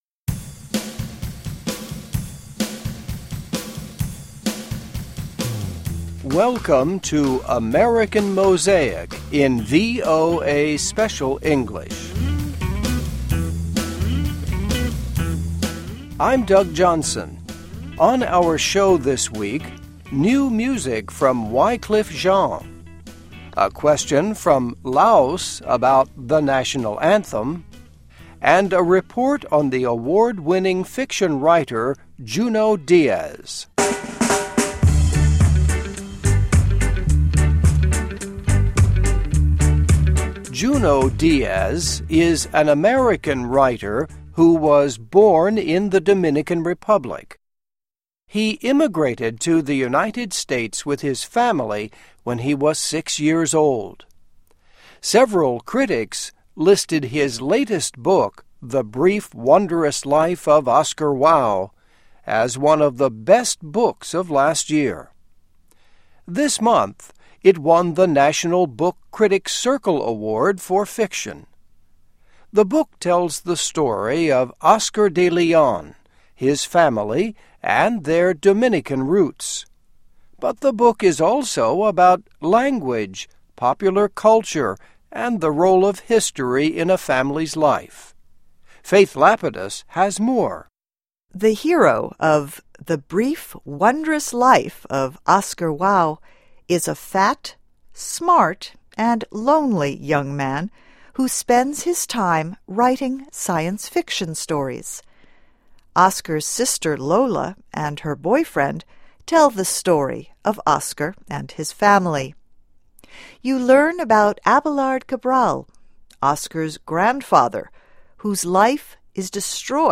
Listen and Read Along - Text with Audio - For ESL Students - For Learning English
Here is a recording of Junot Diaz talking about the effect of using a language that some readers will not understand. He spoke at the Key West Literary Seminar in Florida in January.